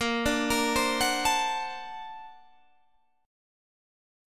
Bb+M9 Chord
Listen to Bb+M9 strummed